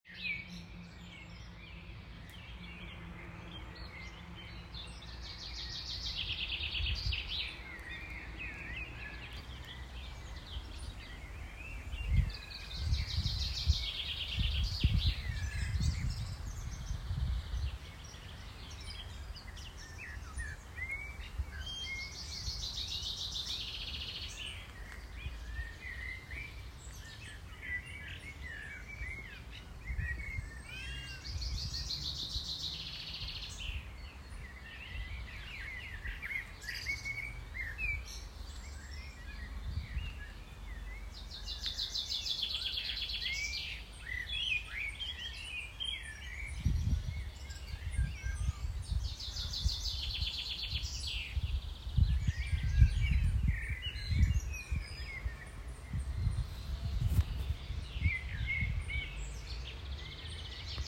vogelgesang.mp3